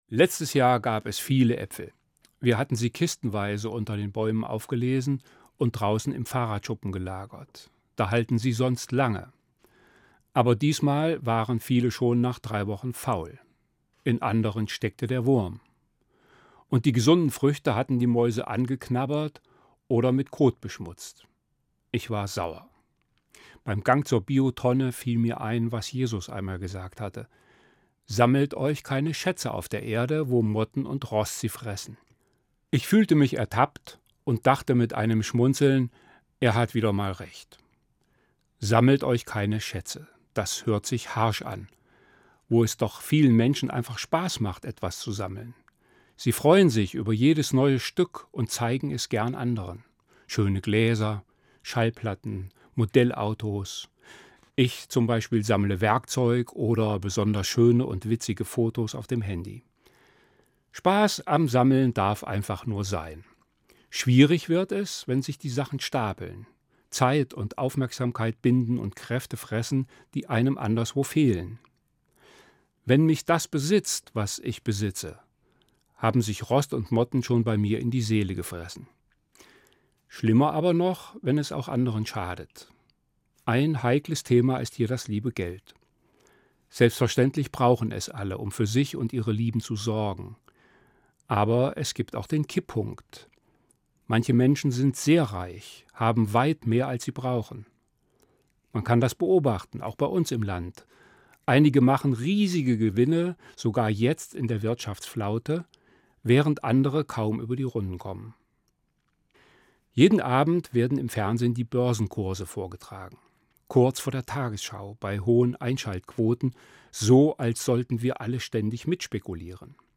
Evangelischer Pfarrer, Marburg